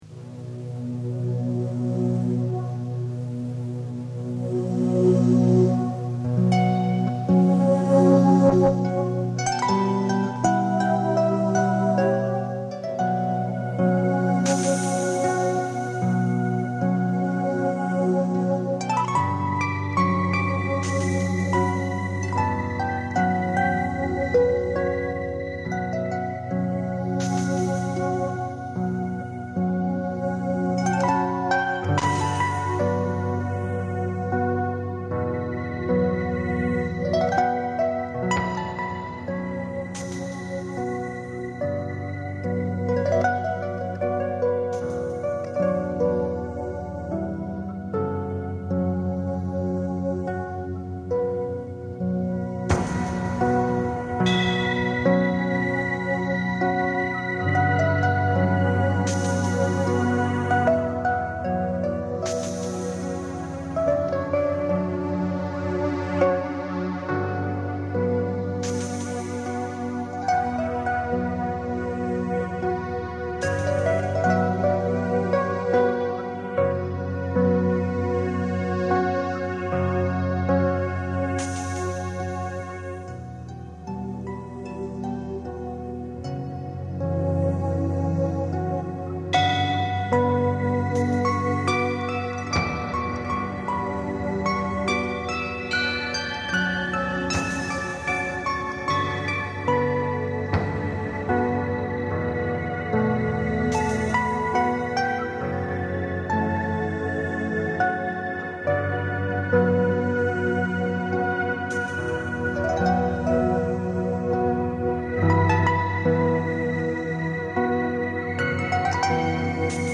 Fedrigotti ha poi scritto qualche brano per arpa che Cecilia Chailly ha eseguito: